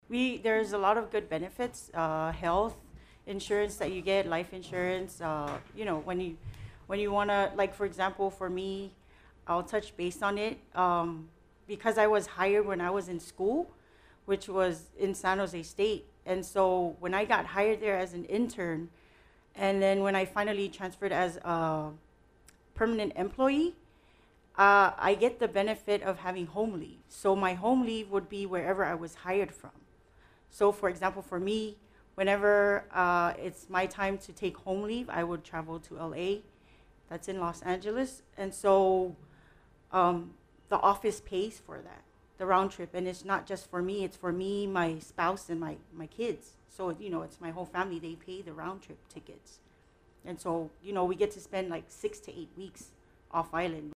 Ahead of the Federal Pathways Career fair that is happening today at the National Weather Service Compound, KHJ’s morning show had some federal employees in the studio to promote the event.